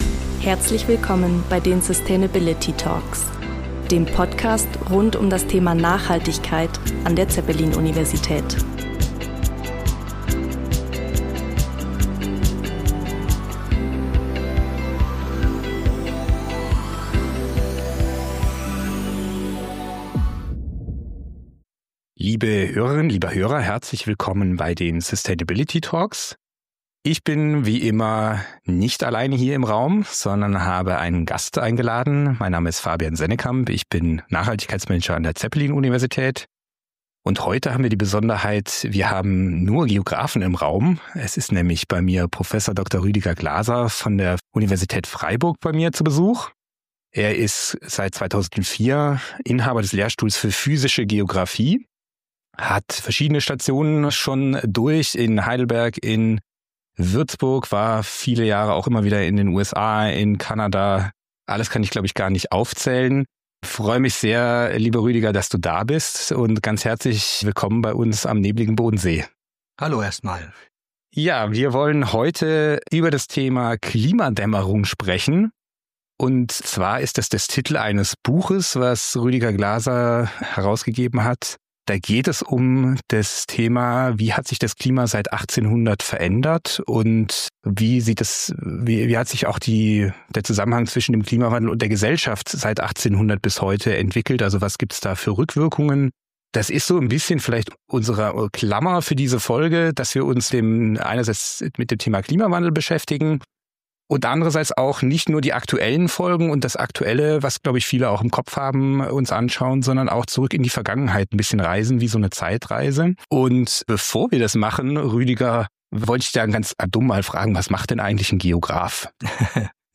Im Zuge des Gesprächs kommen auch die gesellschaftlichen und wirtschaftlichen Auswirkungen des Klimawandels zur Sprache, die sich bereits heute abzeichnen. Ein weiteres Thema der Episode ist, wie verschiedene Wirtschaftssektoren von den Folgen des Klimawandels betroffen sind und ob wir ausreichend auf die Folgen des Klimawandels vorbereitet sind.